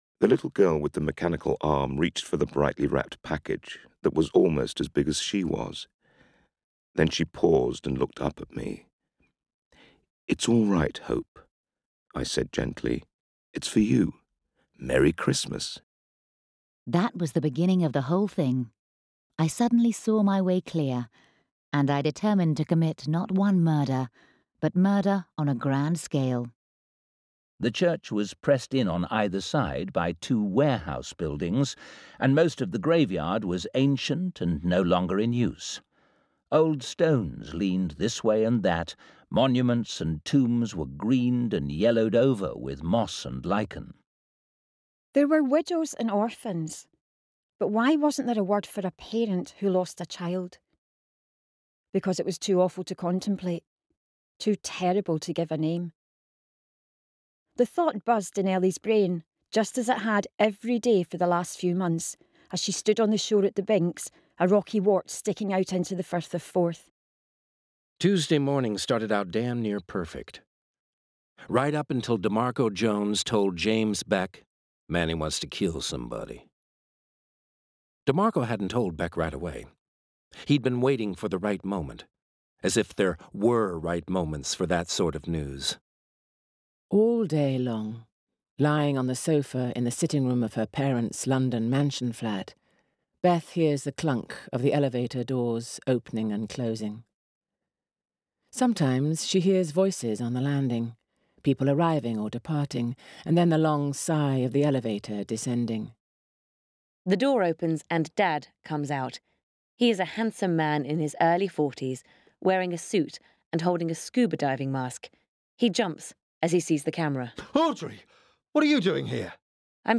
Rushforth Media are one of the UK’s leading audiobook production studios, working for many leading audio publishers, including Blackstone Audio, WF Howes, Audible UK, Bonnier, Bolinda, Naxos and Tantor.
Rushforth-Audiobook-Sampler-copy.mp3